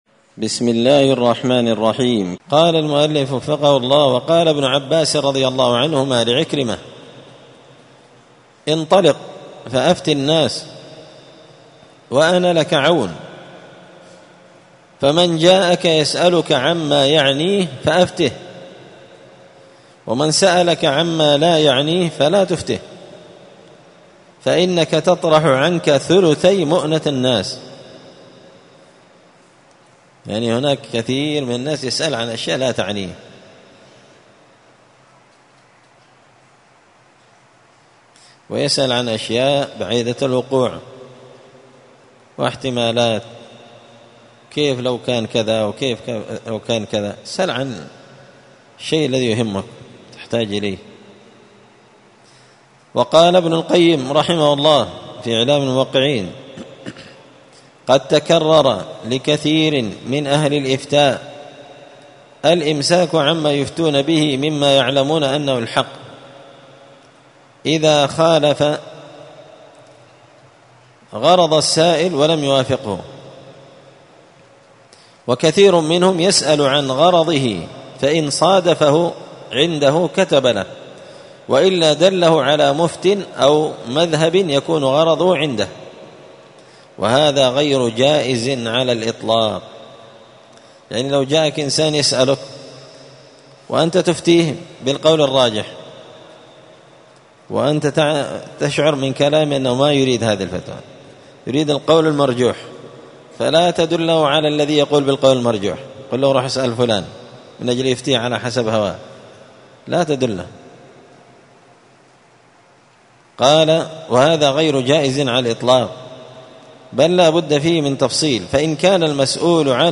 *الدرس الواحد بعد المائة (101) تابع للأدب التسعون لايجاب في كل مسألة ولايفتى كل من سأل*